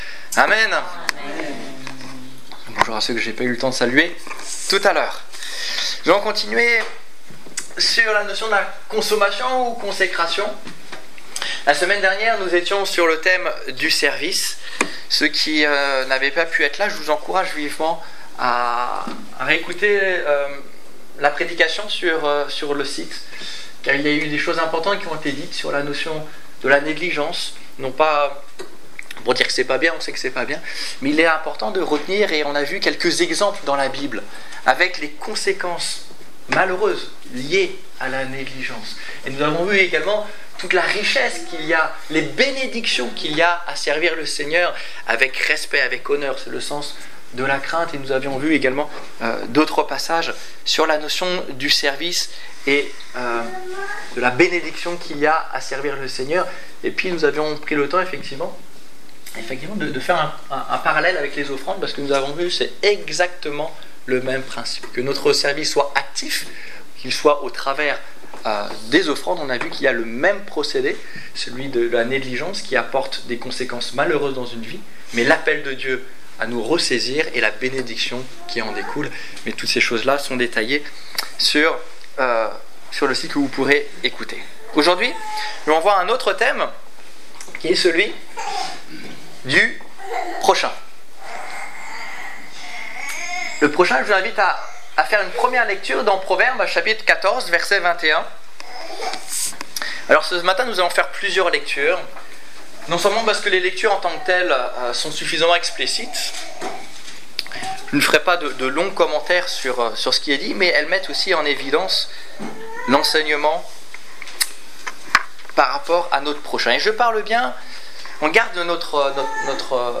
- Mon prochain Détails Prédications - liste complète Culte du 21 juin 2015 Ecoutez l'enregistrement de ce message à l'aide du lecteur